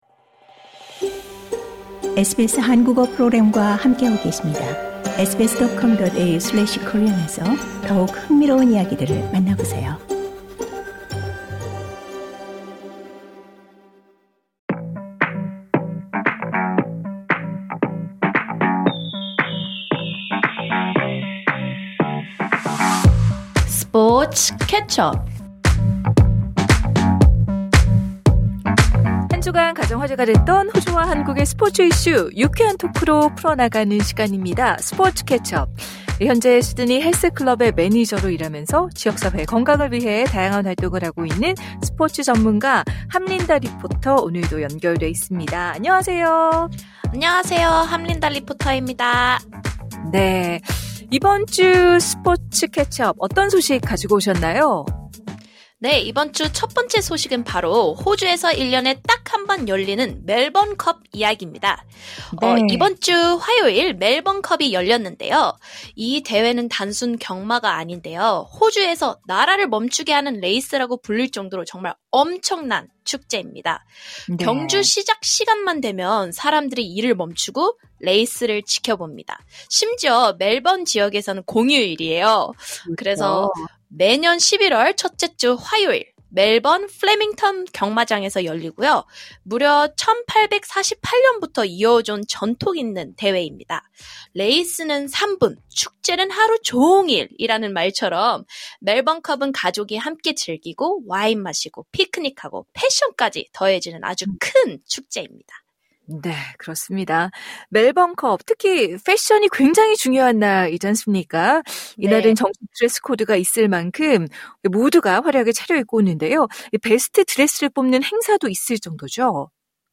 MVP는 야마모토 김혜성, 21년 만에 한국인 월드시리즈 우승 반지 주인공 매주 호주와 한국을 뜨겁게 달군 스포츠 소식을 유쾌한 토크로 풀어내는 시간입니다. 이번 주는 매년 11월 첫째 주 화요일 열리는 호주의 대표 경마축제 ‘멜버른컵’, 그리고 미국 메이저리그 월드시리즈 결승전 소식을 전해드립니다.